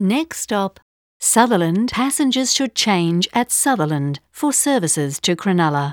On the Illawarra Line, when it approaches a station, a female voice announces: "Next stop, [name]".